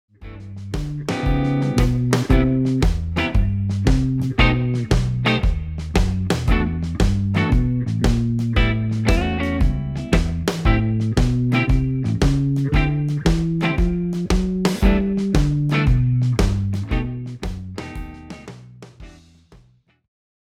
This backing track is in the key of G
||: Em9 – A13 – D9sus – G6/9 B7#5 :||
|| Cmaj7 – B7#5 – Em9 – A13 – D9sus – D9sus – G6/9 – G6/9 ||